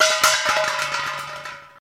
Hubcaps
Hubcap Falling To Ground